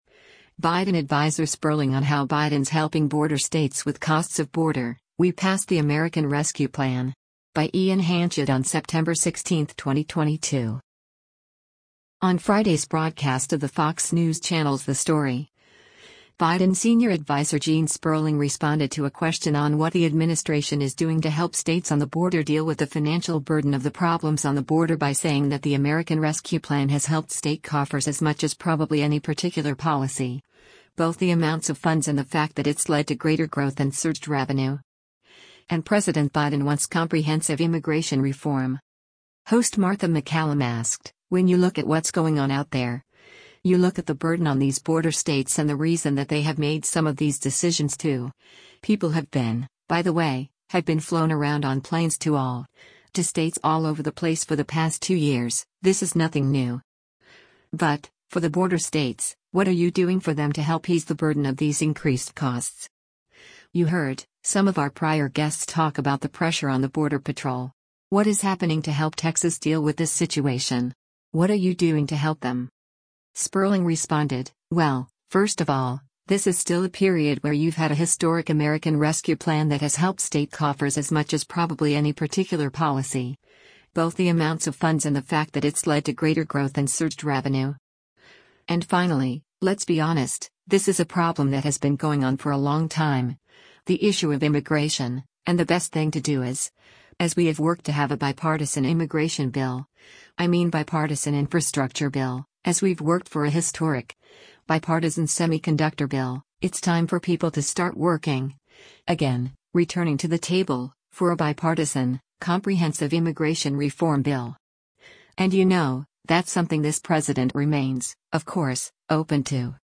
On Friday’s broadcast of the Fox News Channel’s “The Story,” Biden Senior Adviser Gene Sperling responded to a question on what the administration is doing to help states on the border deal with the financial burden of the problems on the border by saying that the American Rescue Plan “has helped state coffers as much as probably any particular policy, both the amounts of funds and the fact that it’s led to greater growth and surged revenue.”